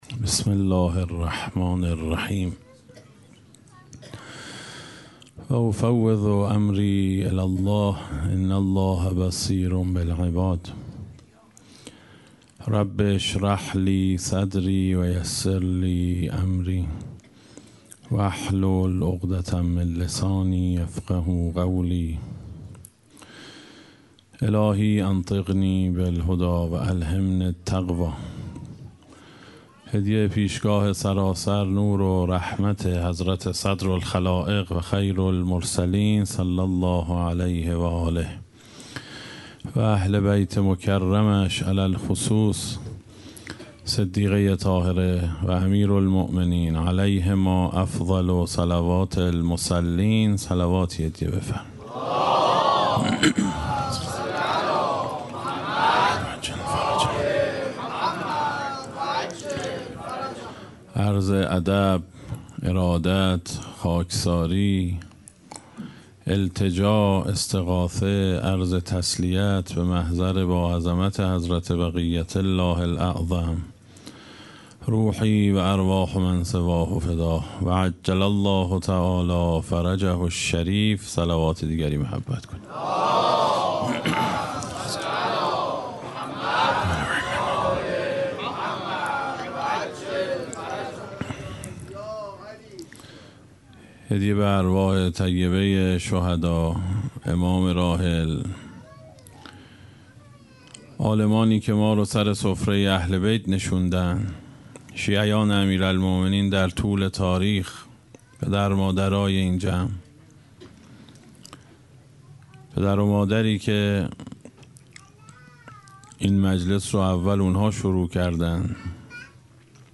به مناسبت ایام فاطمیه اول در هیئت محترم متوسلین به حضرت مسلم بن عقیل علیهما السلام
اشتراک گذاری دسته: الهیات شکست , حضرت فاطمه سلام الله علیها , سخنرانی ها قبلی قبلی الهیات شکست؛ فاطمیه اول – جلسه نهم از ده جلسه بعدی فاطمیه در برابر طغیان کفر؛ جلسه اول بعدی